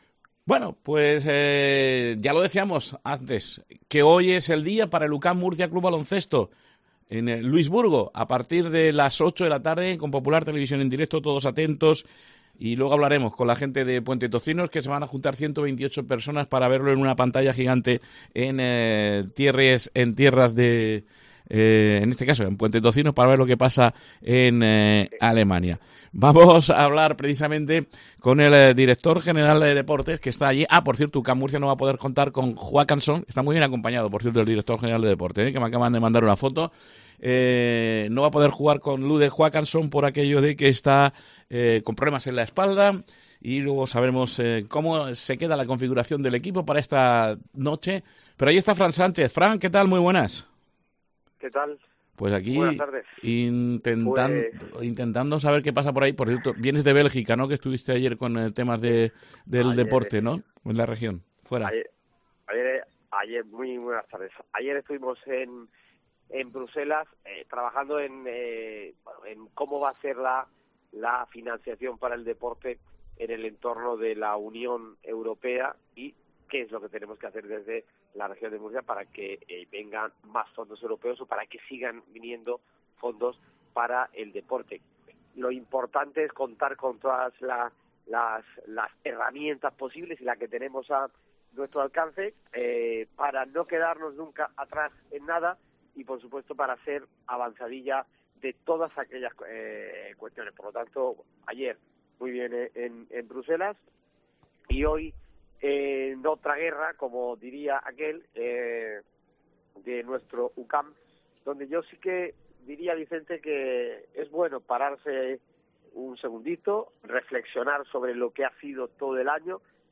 AUDIO: El director general de Deportes analiza en COPE Murcia el partido de esta noche en tierras alemanas frente al Riesen
ENTREVISTA